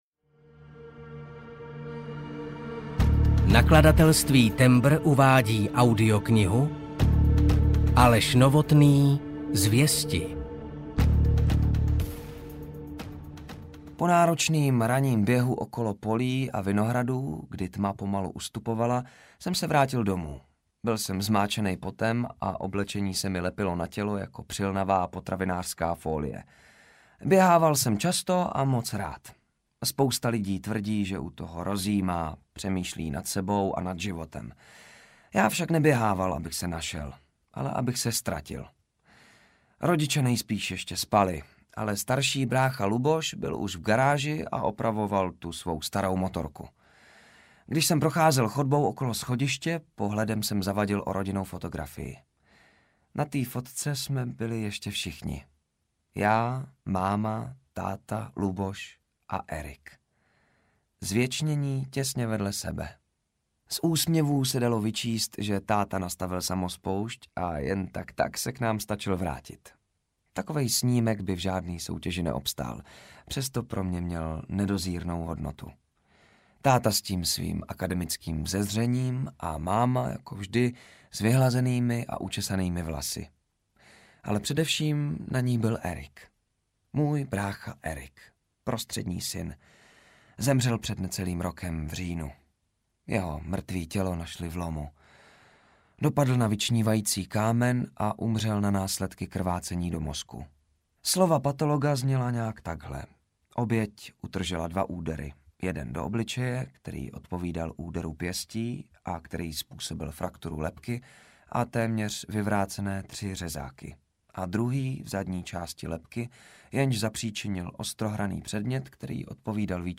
Zvěsti audiokniha
Ukázka z knihy